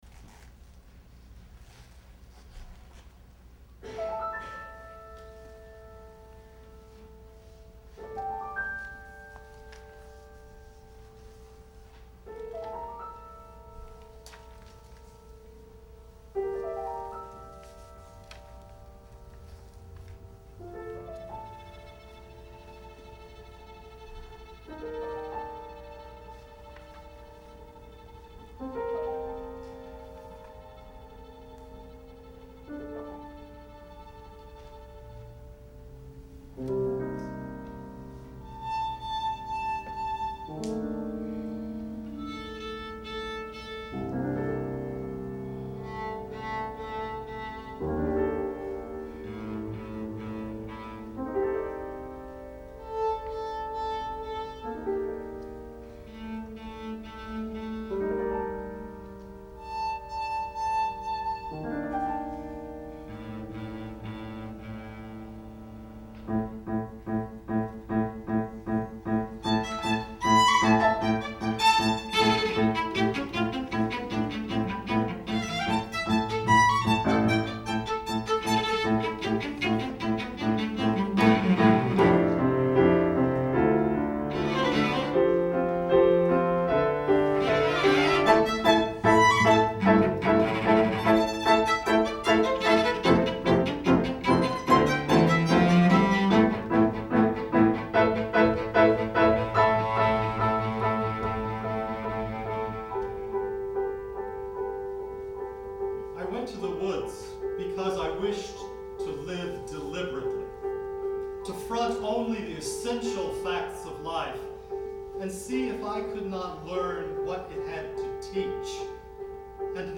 Violin
Cello
Piano